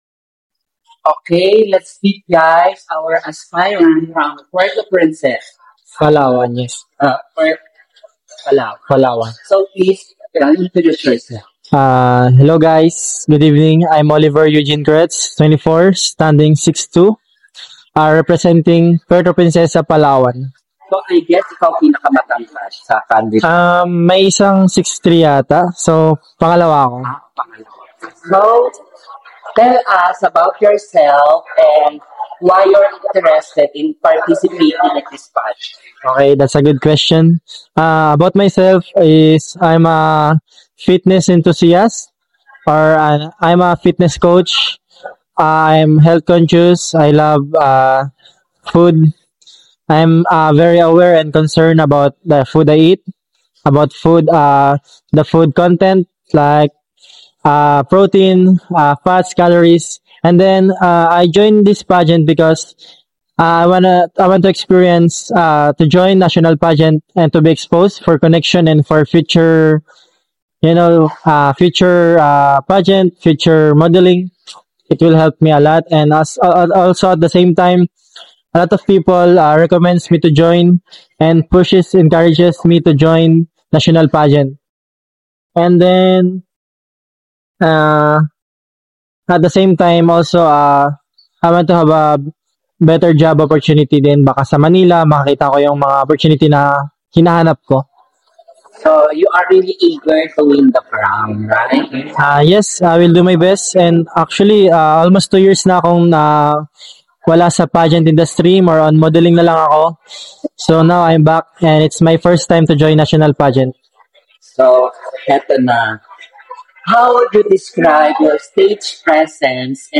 💥 EXCLUSIVE INTERVIEW